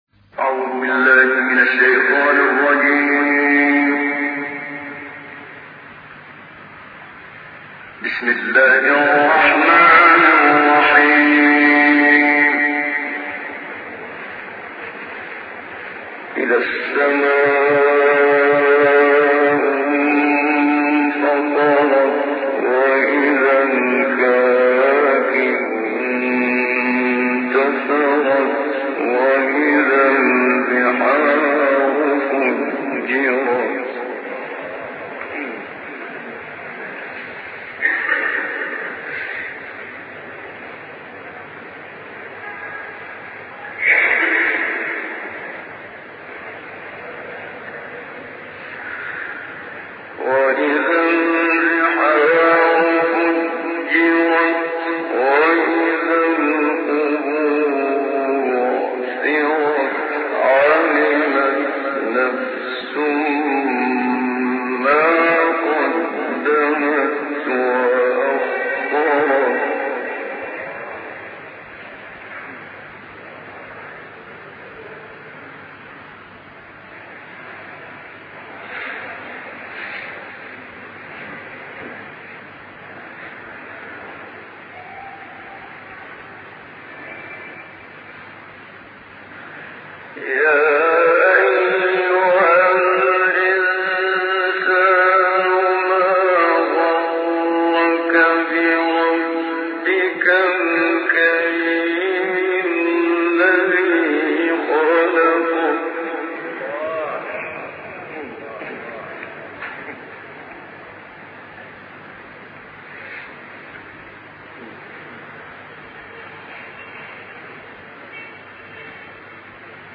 این تلاوت زیبا به صوت استاد منشاوی را می توانید با مراجعه به ادامه مطلب دریافت نمائید.
قرائت مجلسی " انفطار ، فجر و بلد " به صوت استاد محمد صدیق منشاوی